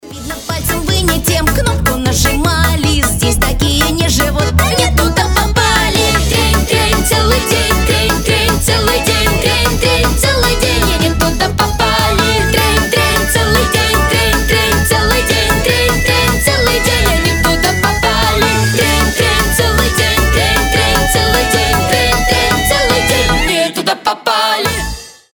Веселые
Детские